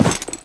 脚步声－穿铁靴-YS070521.wav
通用动作/01人物/01移动状态/脚步声－穿铁靴-YS070521.wav
• 声道 單聲道 (1ch)